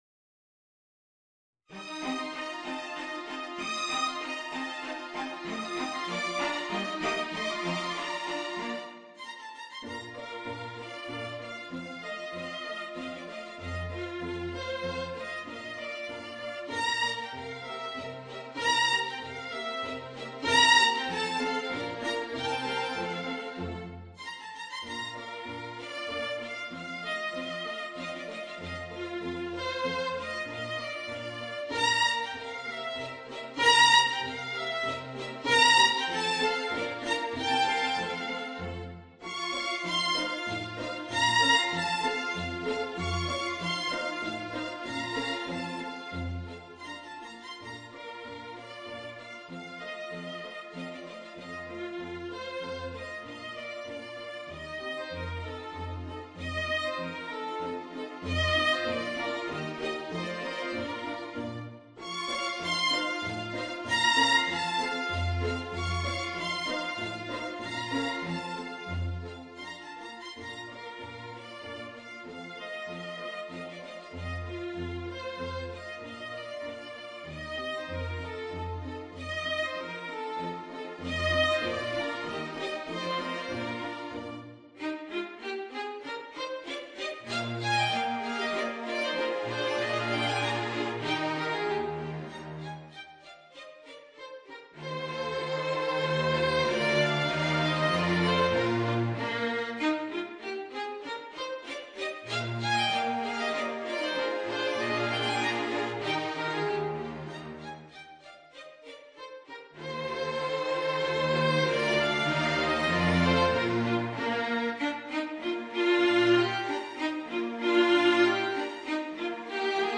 Voicing: Clarinet and String Quartet